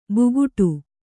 ♪ buguṭu